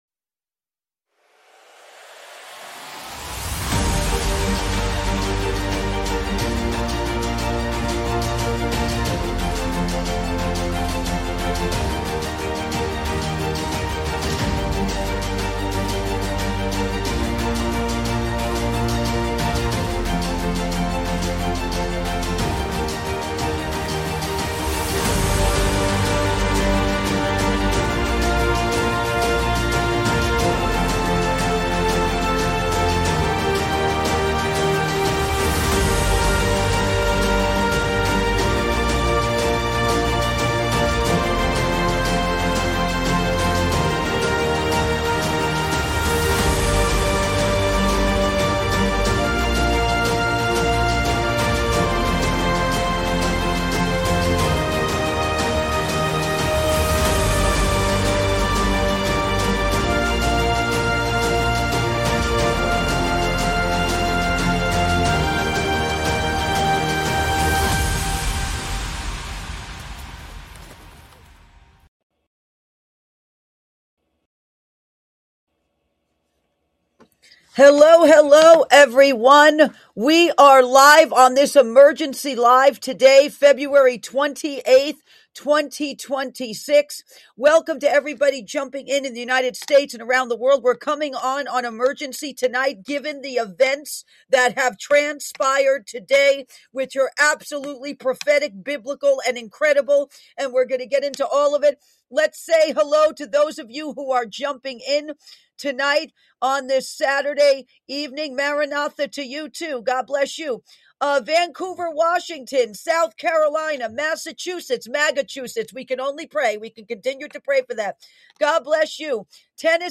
Talk Show Episode, Audio Podcast, Ark of Grace and URGENT Broadcast: US & ISRAEL BOMB IRAN! Operation Roaring Lion, The Ayatollah is dead! on , show guests , about US & ISRAEL BOMB IRAN,Operation Roaring Lion,The Ayatollah is dead,Prophetic Fulfillment,Fall of the Iranian Regime,The hunter has become the hunted,The Fall of the Ayatollah,toppling the Iranian regime,EPIC FURY,Spiritual Warfare and Church Vigilance, categorized as News,Paranormal,Politics & Government,Religion,Christianity,Self Help,Society and Culture,Spiritual,Theory & Conspiracy